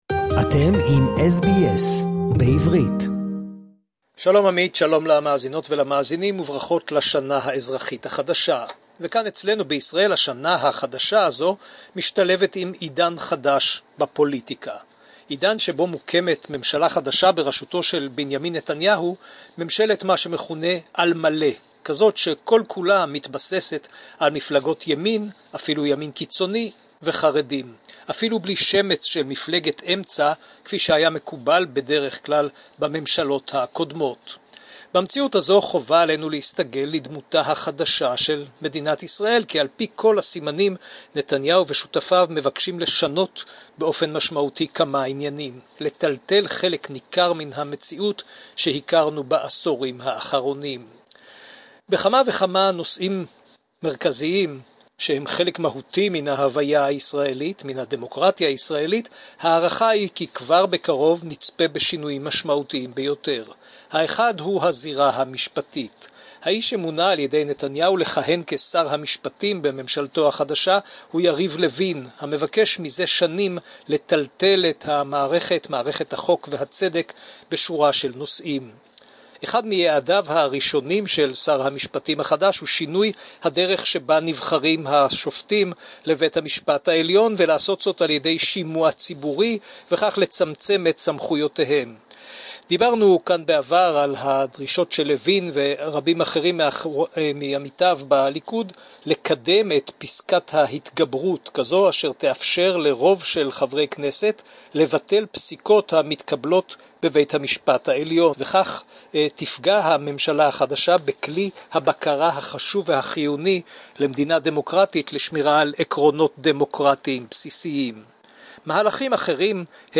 reports on the latest news and events from Israel